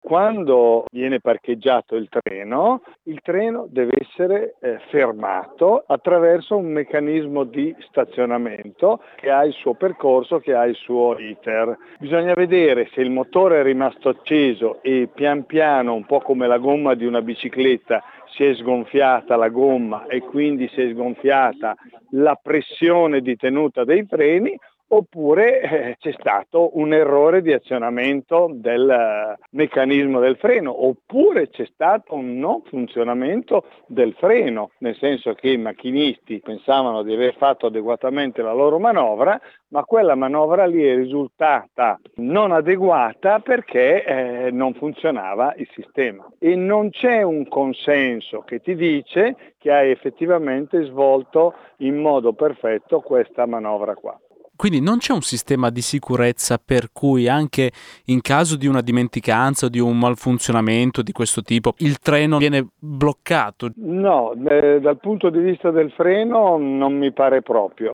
ferroviere ed ex sindacalista